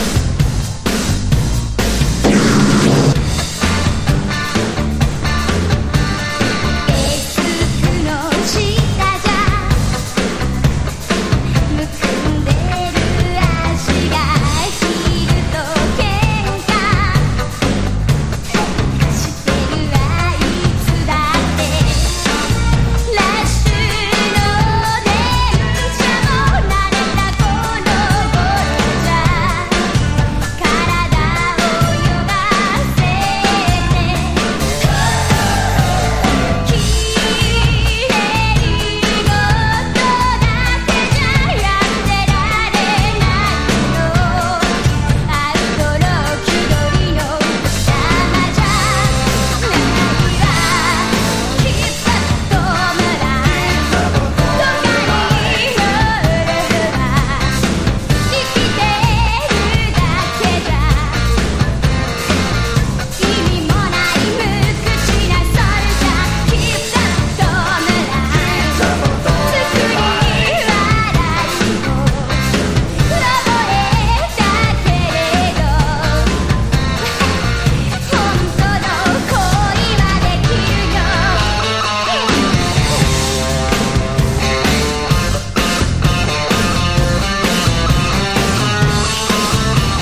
3曲とも80年代和モノ・シンセ・ダンストラックな仕上がりで◎。
# 60-80’S ROCK# POP# 和モノ